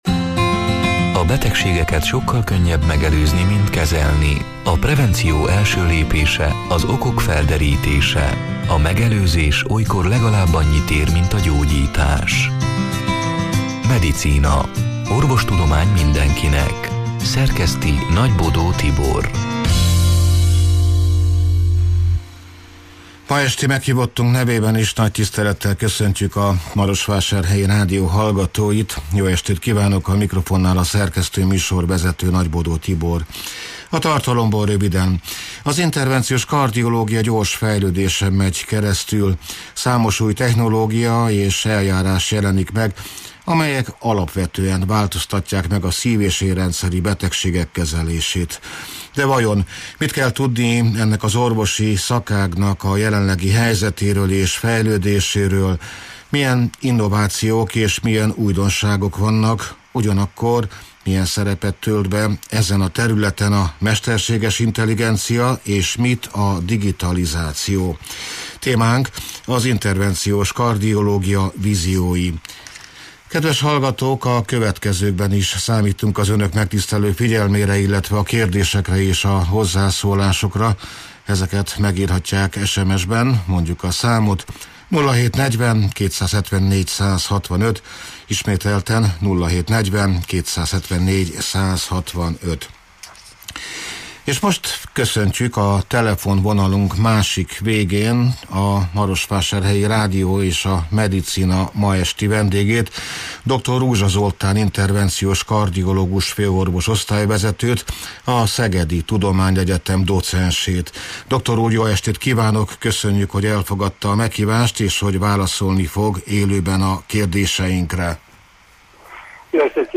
(elhangzott: 2025. január 29-én, szerdán este nyolc órától élőben)